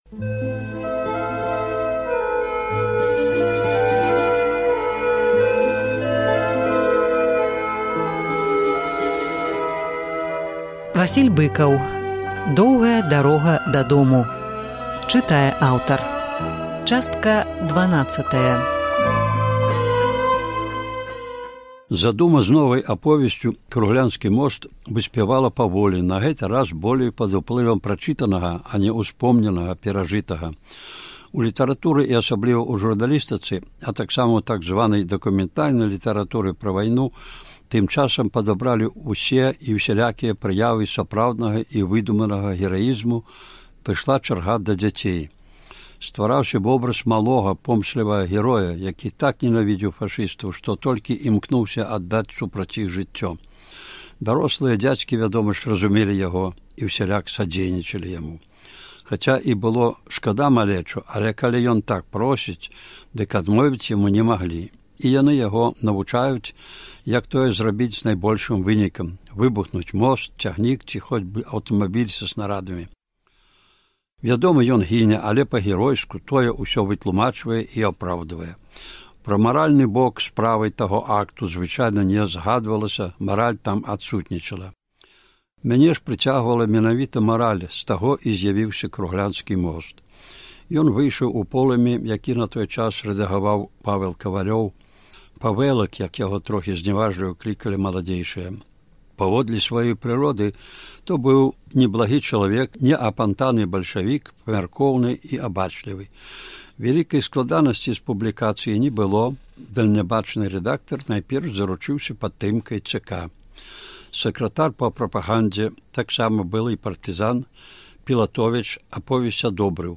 Успаміны Васіля Быкава «Доўгая дарога дадому». Чытае аўтар.